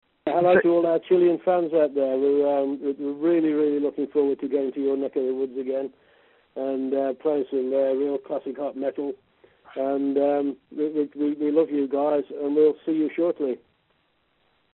Para terminar Ian Hill dejó un saludo para todos sus fans y una invitación para que asistan al concierto el próximo 5 de mayo.